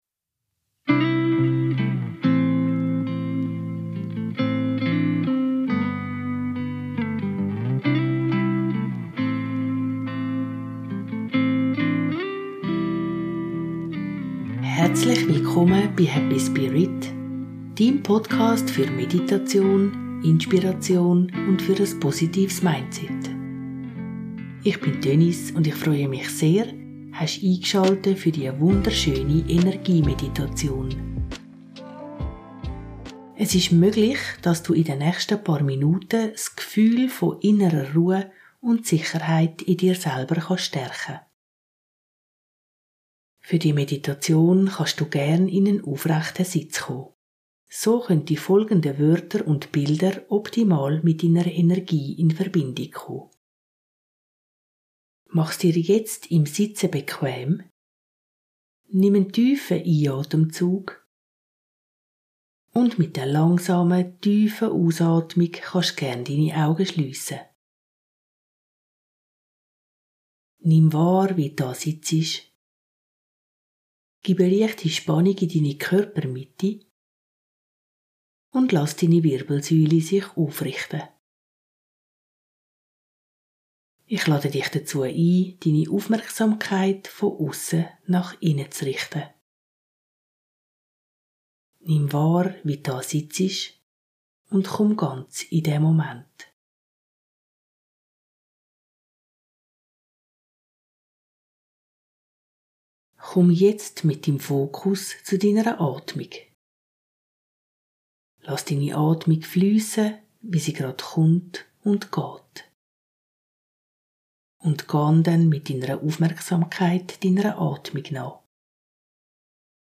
#59 Energie-Meditation für innere Ruhe und Sicherheit